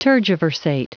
Prononciation du mot tergiversate en anglais (fichier audio)
tergiversate.wav